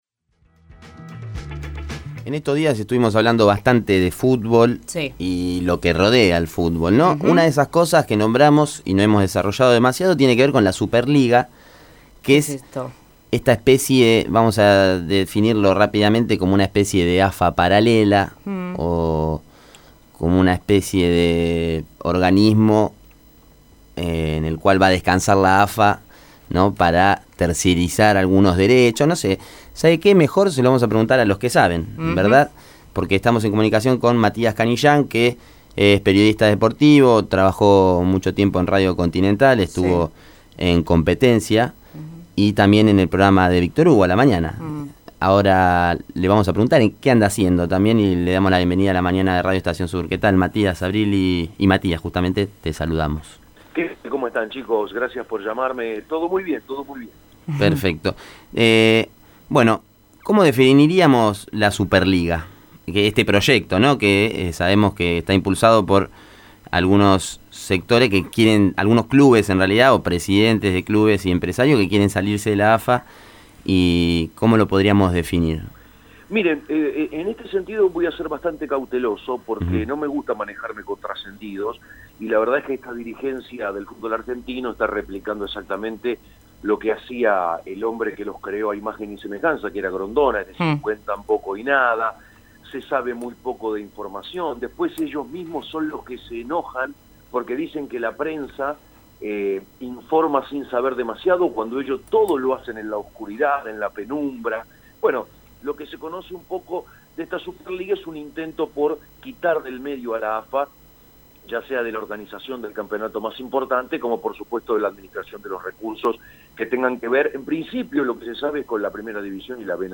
Clase Turista entrevisto al reconocido periodista deportivo